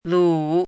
《鏀》,鏀的意思|鏀的读音
注音： ㄌㄨˇ
lu3.mp3